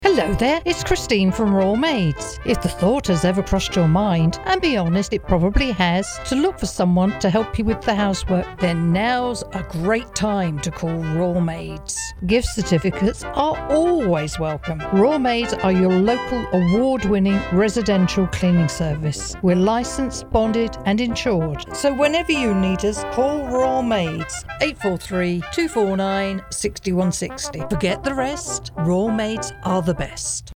Listen to our ad on Talk Radio 99.5 FM